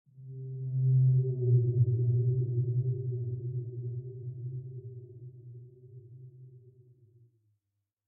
File:Sfx creature glowwhale call 06.ogg - Subnautica Wiki
Sfx_creature_glowwhale_call_06.ogg